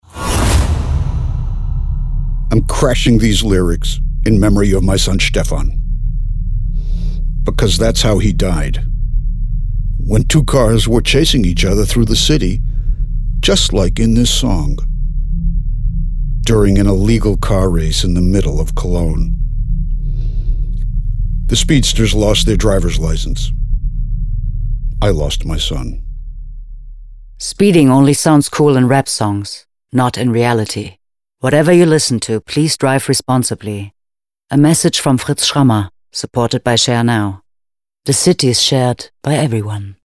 L’argent ex æquo  pour 3 spots de la campagne allemande « Crashed Lyrics », une sensibilisation audacieuse et originale à la conduite responsable financée par la société de co-voiturage Share Now. Sur une radio qui a accepté le challenge, les spots étaient diffusés en interruption de chansons dans lesquels la conduite rapide, les comportements à risques, figurent dans les paroles.